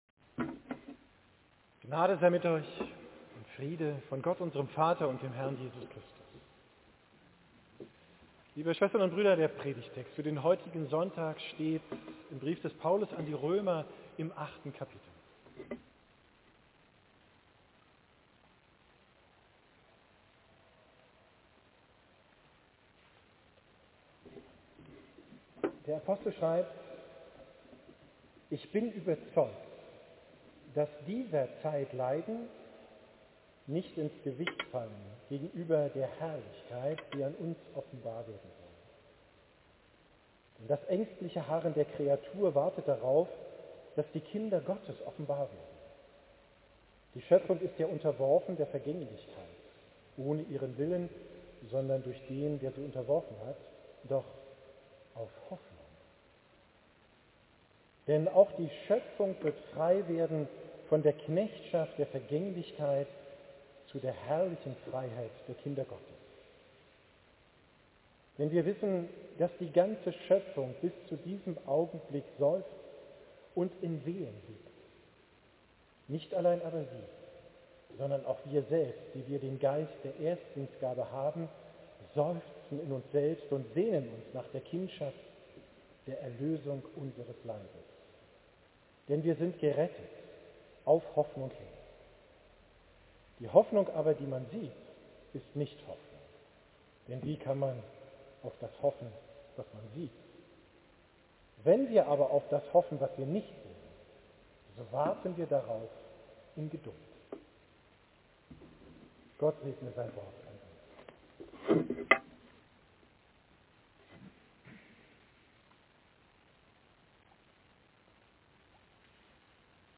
Predigt vom drittletzten Sonntag im Kirchenjahr, 12.